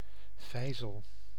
Ääntäminen
IPA: [lə mɔʁ.tje]